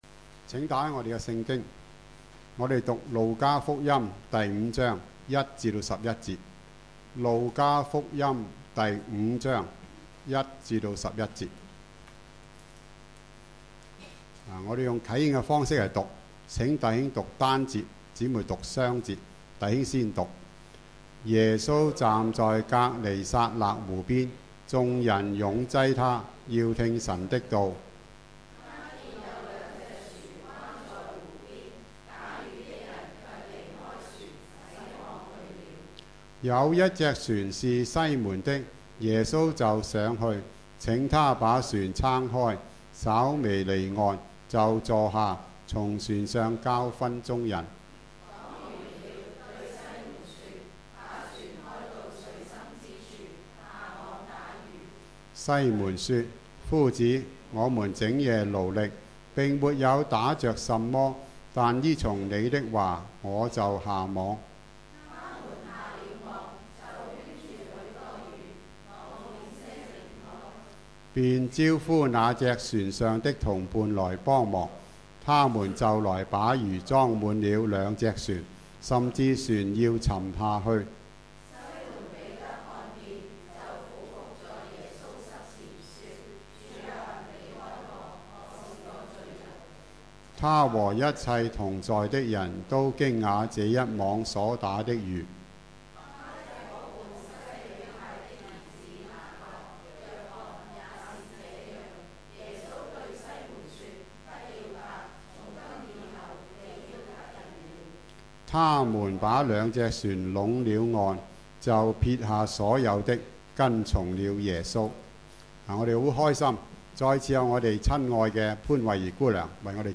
主日崇拜講道 – 進入深處滿載而歸